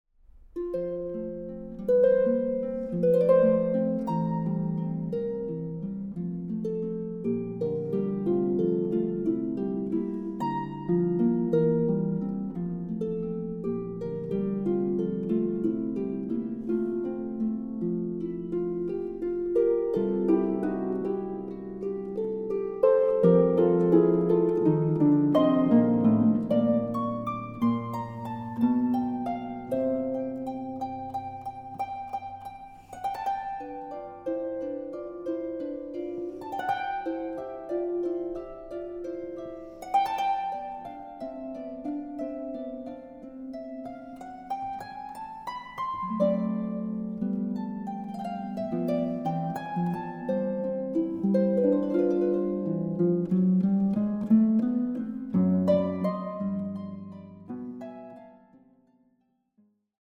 Harfe
Aufnahme: Festeburgkirche Frankfurt, 2024